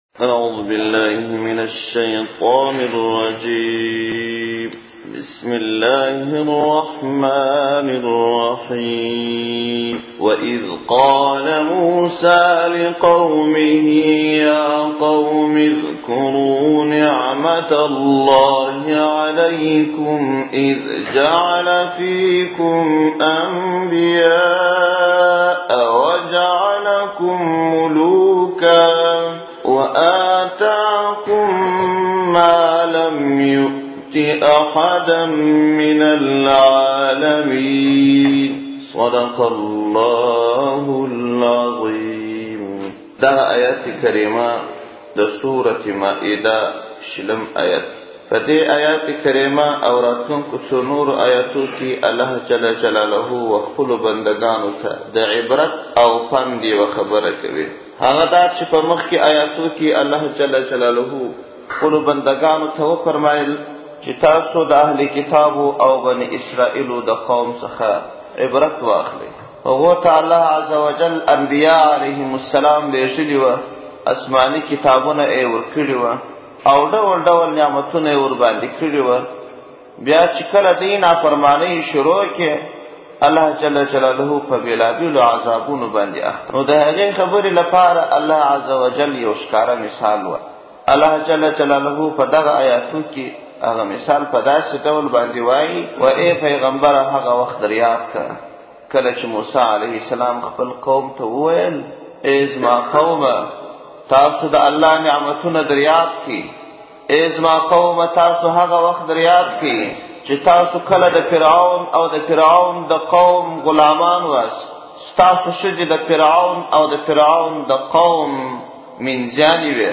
پښتو – دقرآن کریم تفسیر او ترجمه